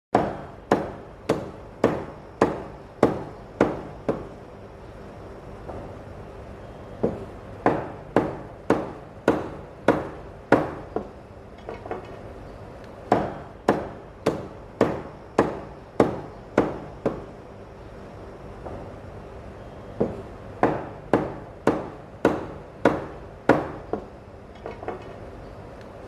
Tiếng Chặt Thịt đều đặn trên thớt
Tiếng Chặt Thịt Gà Tiếng dùng dao Thái Thịt trên thớt
Thể loại: Tiếng ăn uống
Description: Tiếng bổ thịt, tiếng băm thịt, tiếng chém thịt, tiếng dao chặt, tiếng dao phay trên thớt, tiếng chặt thực phẩm, âm thanh “cộp… cộp… cộp” vang lên nhịp nhàng khi lưỡi dao phay chạm xuống thớt gỗ hoặc thớt nhựa, tạo cảm giác chắc tay và chuyên nghiệp của người đầu bếp.
tieng-chat-thit-deu-dan-tren-thot-www_tiengdong_com.mp3